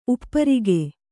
♪ upparige